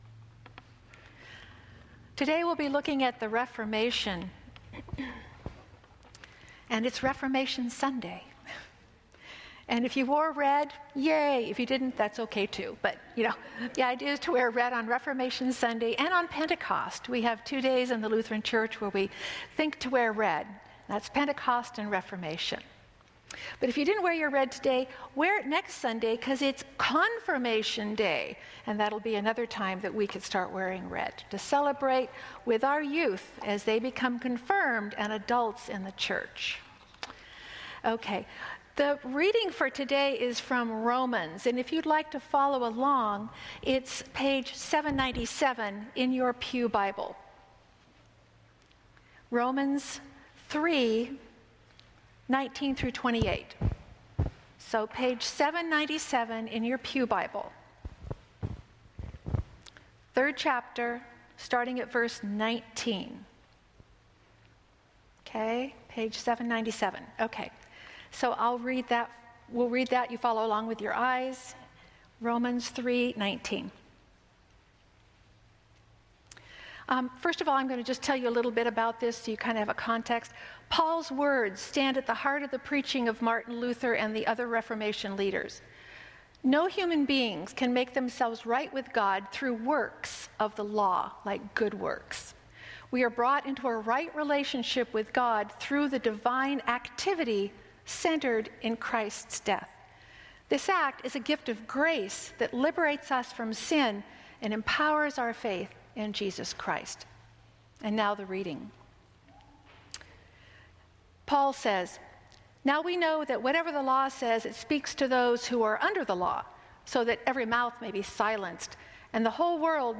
Our Redeemer Lutheran Church Garden Grove Sermons